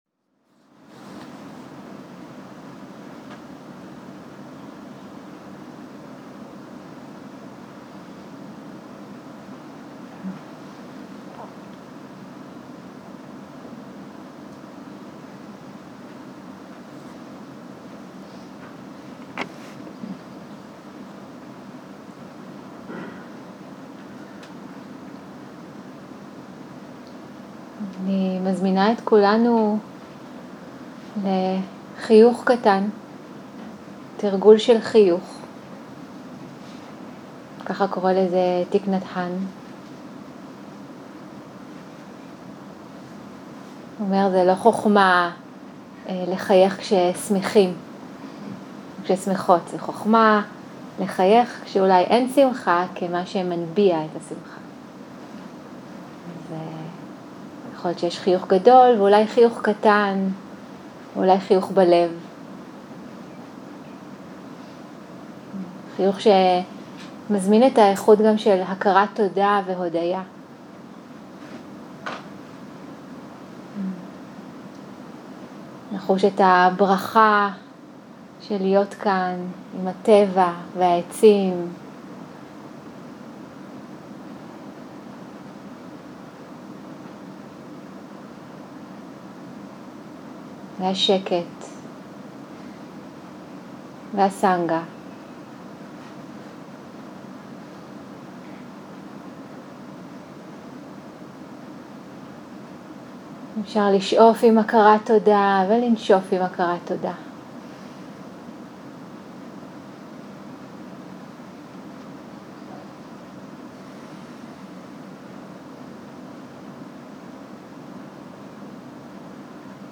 יום 6 - בוקר - הנחיות מדיטציה - אי האחזות וכיווץ - הקלטה 14
סוג ההקלטה: שיחת הנחיות למדיטציה